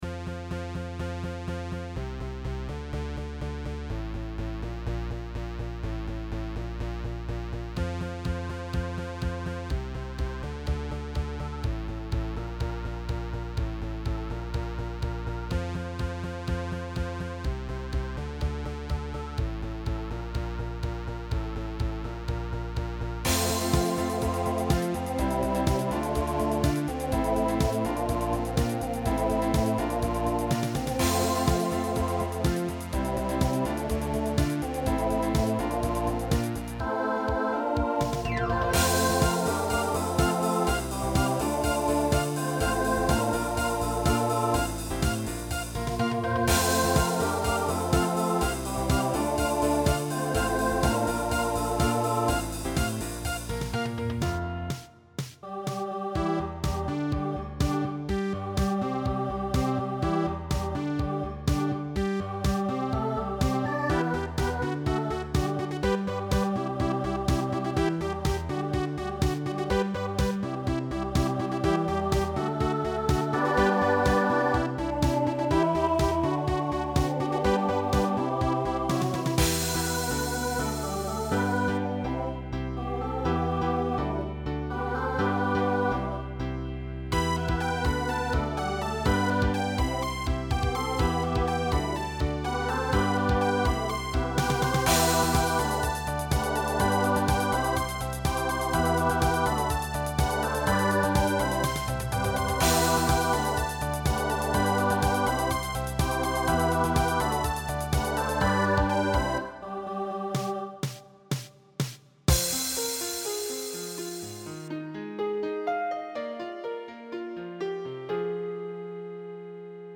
Voicing Mixed Instrumental combo Genre Pop/Dance